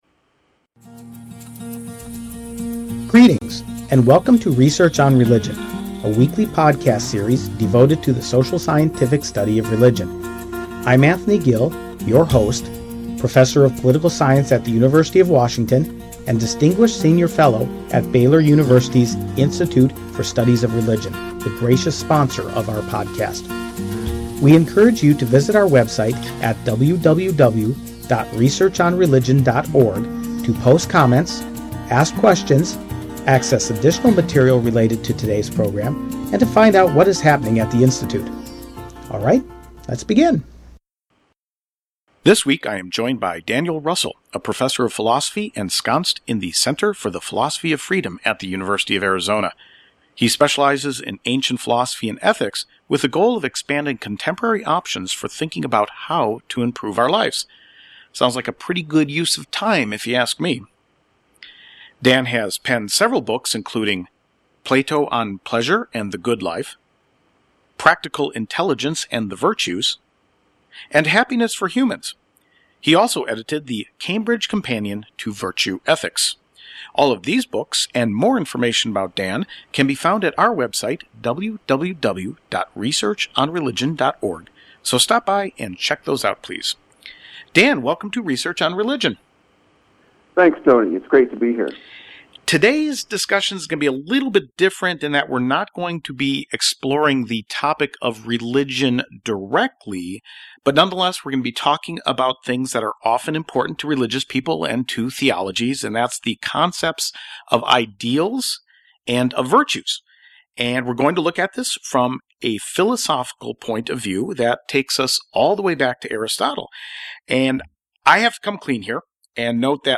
This interview represents a slight departure from our normal fare in that we do not address issues of religion directly, although the topic of virtue lingers large in theological thinking and in ritual practice.